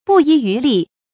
不遗余力 bù yí yú lì
不遗余力发音
成语正音 不，不能读作“bú”